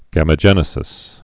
(gămə-jĕnĭ-sĭs)